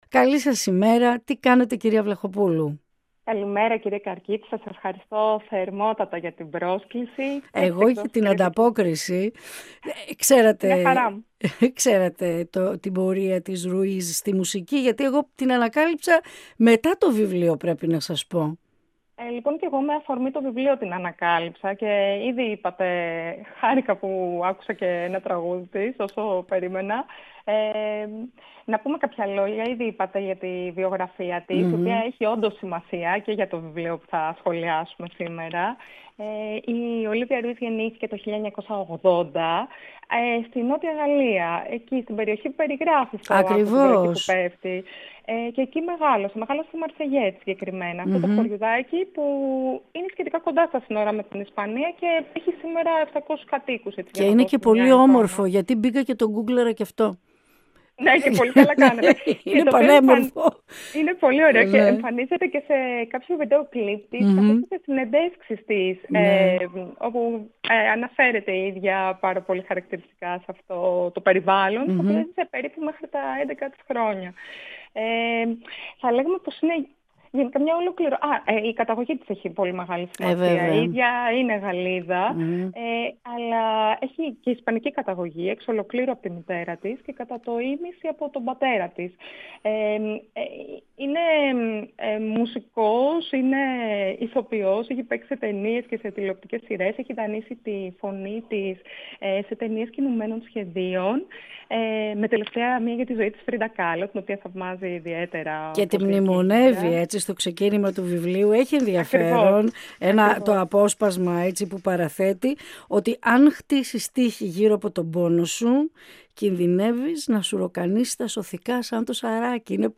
Ολιγη Κινηση του Δρομου και των Μαγαζιων Συνεντεύξεις